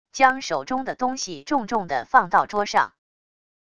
将手中的东西重重的放到桌上wav音频